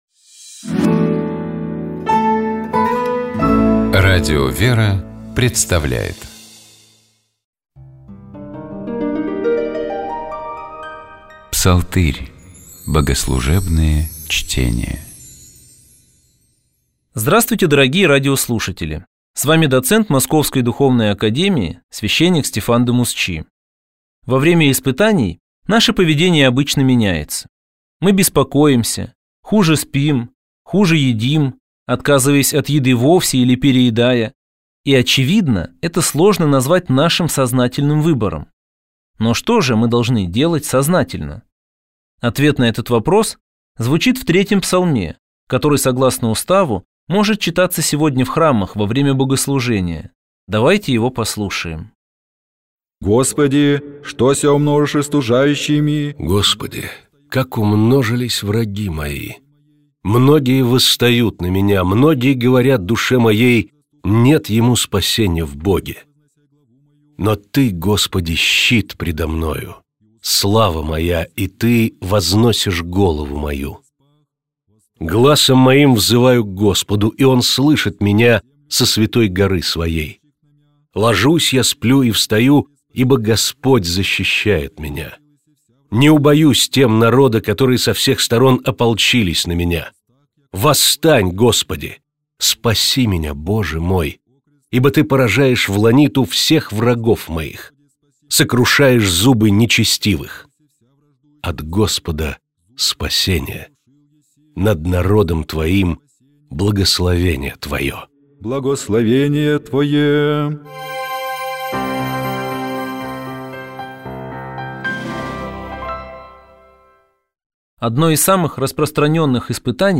Давайте попробуем разобраться, о чём это песнопение, и послушаем его отдельными фрагментами в исполнении сестёр Орского Иверского женского монастыря.